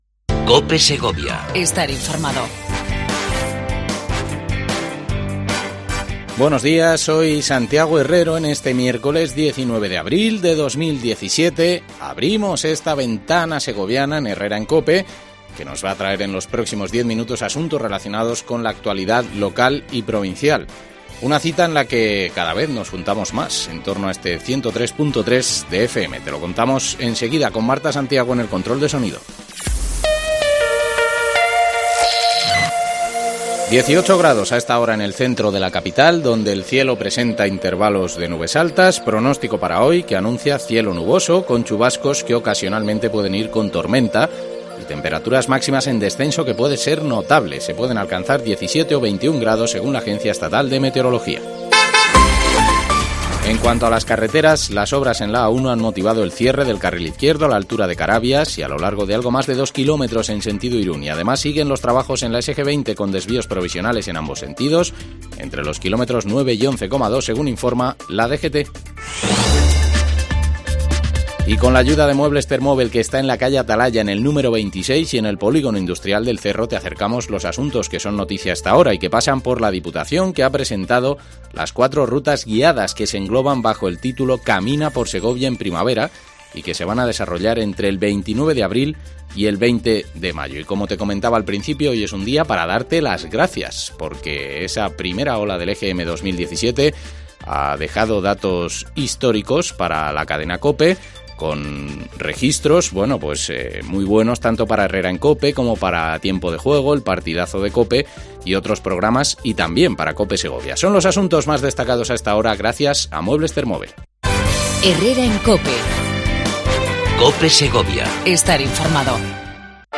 Entrevista con el Concejal de Medio Ambiente